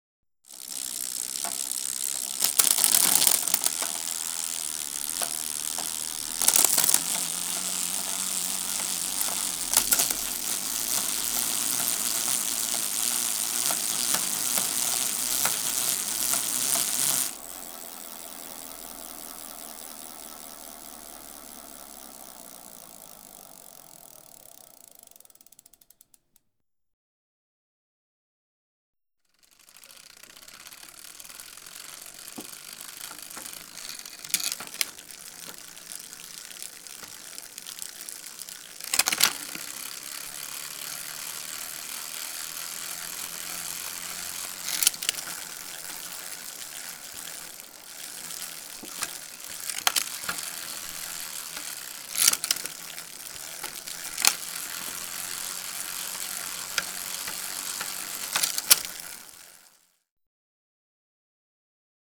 Bicycle Gear Chain Shift Gears Sound
transport